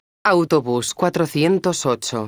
autobus_408.wav